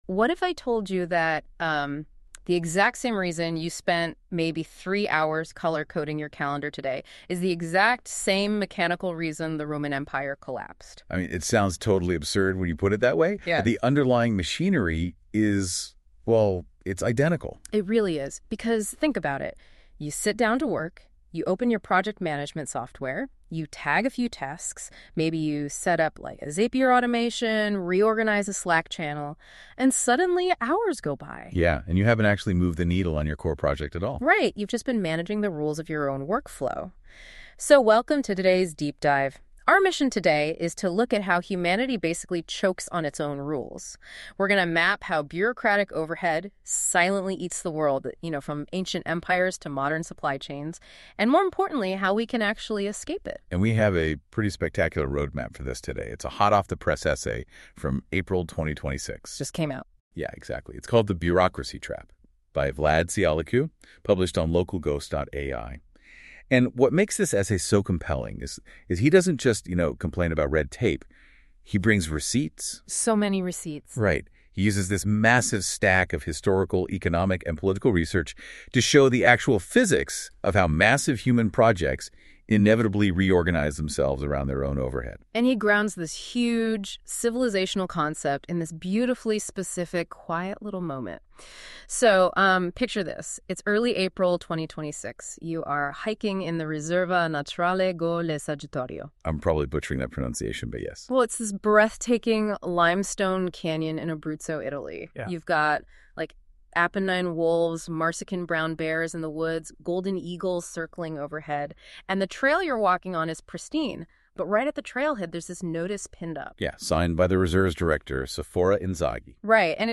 > EPISODE 10 // OFFLINE-READY NOTEBOOKLM AUDIO ▶ ❚❚ 0:00 / 0:00 DOWNLOAD > 1.